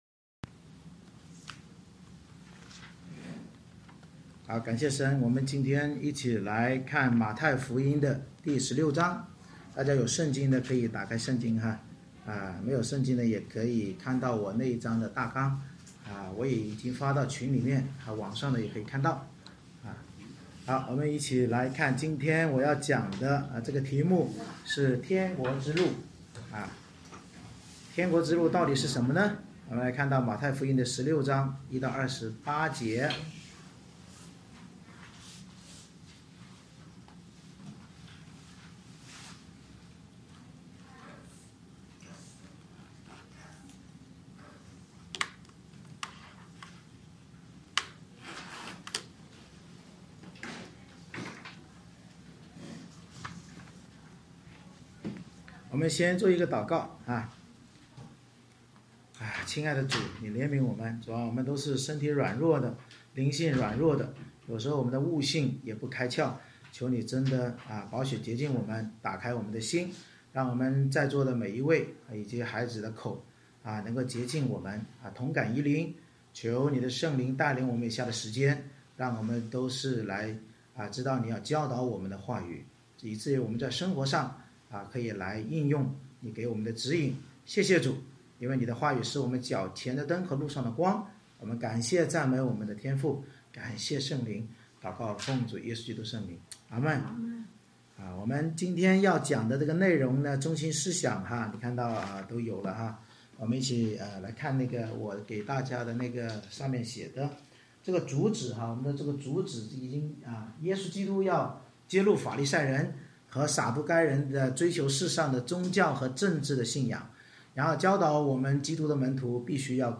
马太福音16章 Service Type: 主日崇拜 耶稣揭露法利赛人和撒都该人追求世上宗教和政治信条，教导我们基督徒必须跟从基督走十字架舍己之路才能看见并进入永生神儿子的国度。